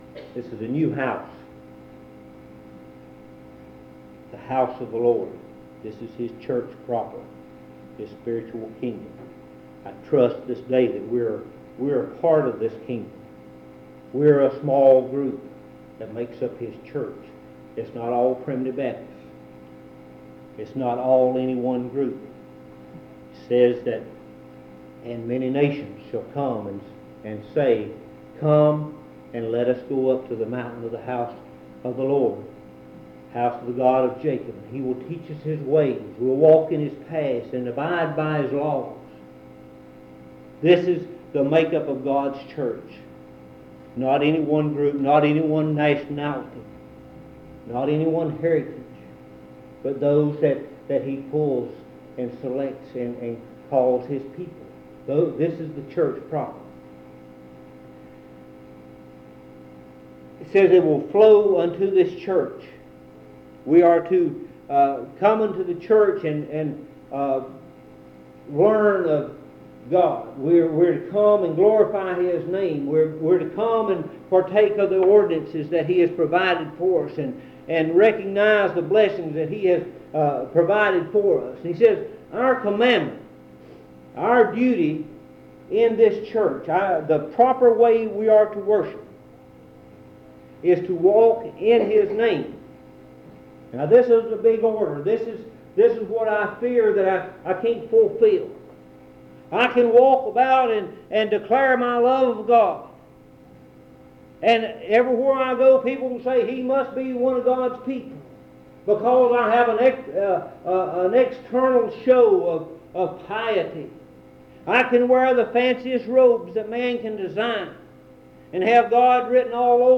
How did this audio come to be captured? In Collection: Monticello Primitive Baptist Church audio recordings Thumbnail Title Date Uploaded Visibility Actions PBHLA-ACC.002_001-B-01.wav 2026-02-12 Download PBHLA-ACC.002_001-A-01.wav 2026-02-12 Download